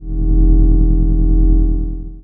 hum_electric_neon_light_01.wav